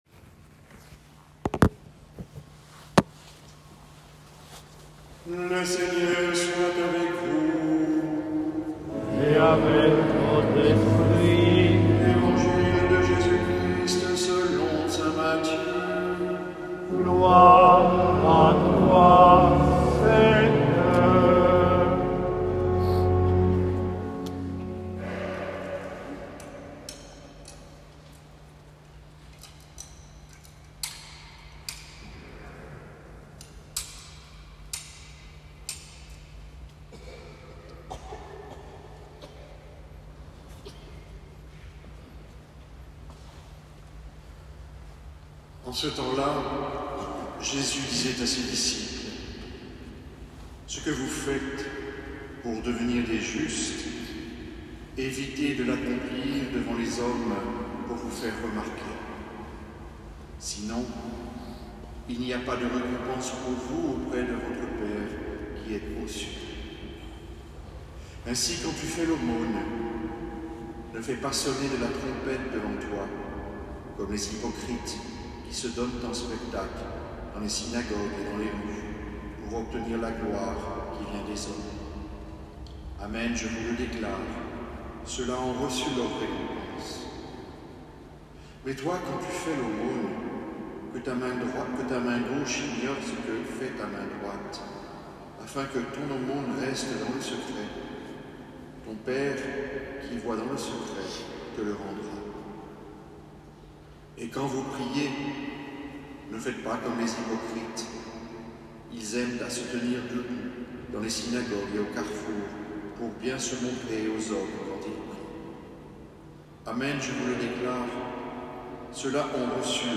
Homélie – mercredi des cendres